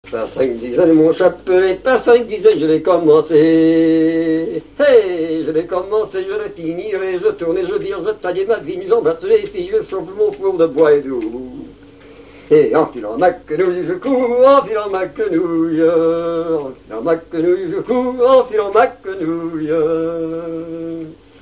Note grand'danse
Vendée
Genre énumérative
Catégorie Pièce musicale inédite